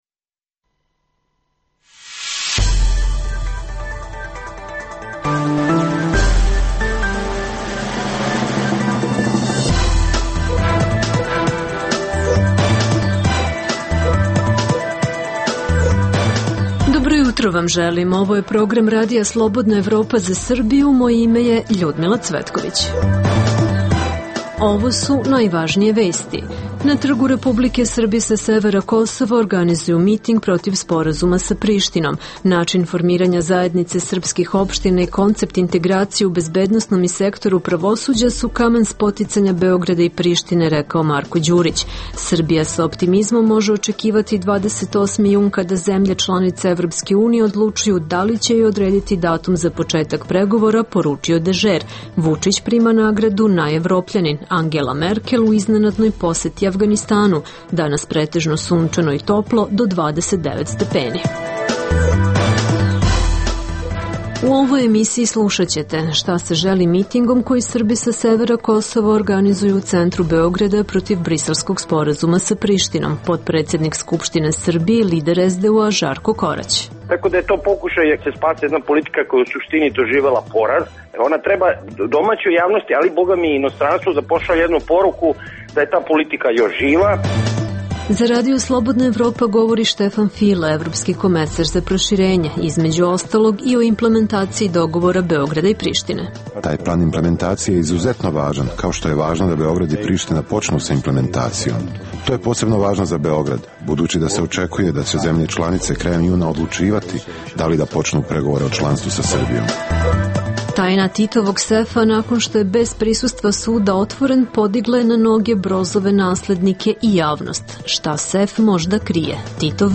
U emisiji poslušajte: - Šta se želi mitingom koji Srbi sa severa Kosova organizuju u centru Beograda protiv briselskog sporazuma sa Prištinom? - Za RSE govori Štefan File, evropski komesar za proširenje.